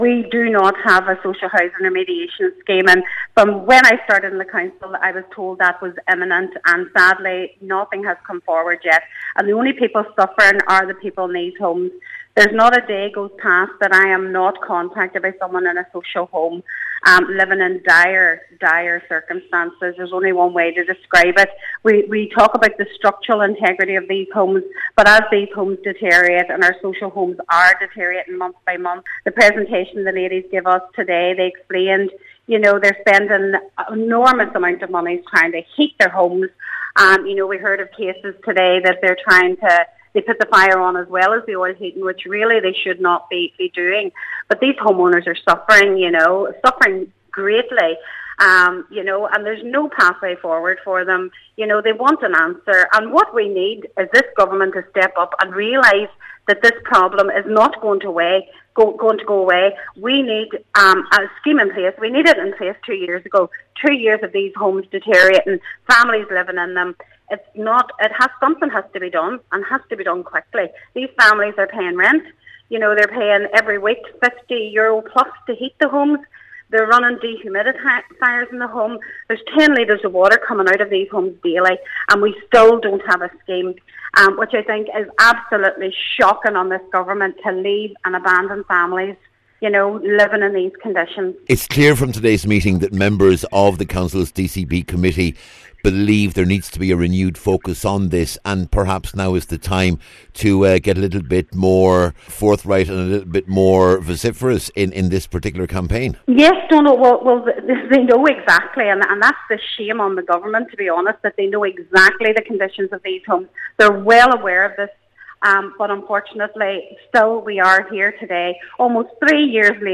Cllr Joy Beard said it’s a disgrace that a scheme that was promised over two years ago has still not materialised……..